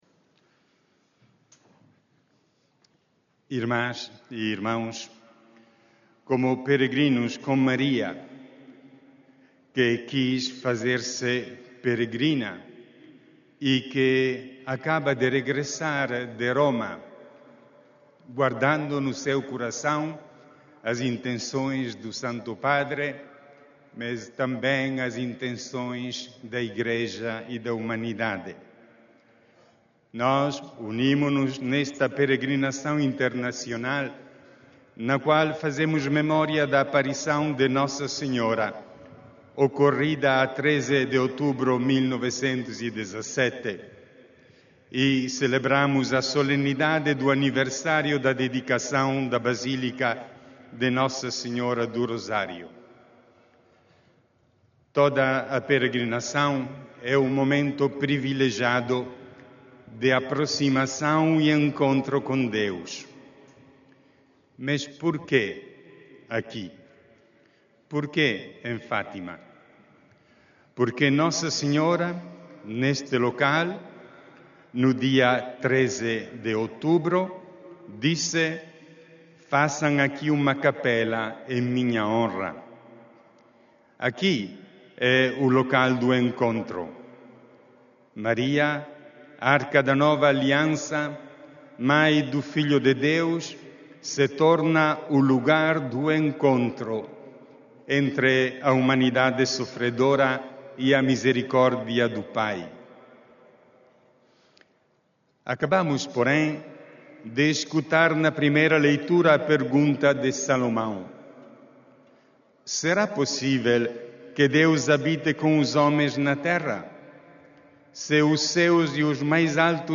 D. Claudio Dalla Zuanna, que presidiu à Missa Internacional Aniversária deste 13 de outubro, no Recinto de Oração do Santuário, exortou os cerca de 110 mil peregrinos ali reunidos a participarem na construção da paz através de uma mudança pessoal.